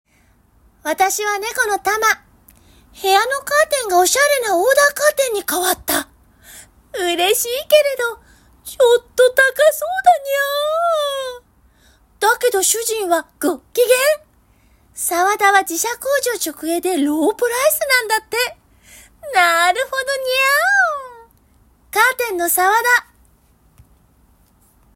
ナレーション音源サンプル　🔽
【キャラクター系ナレーション】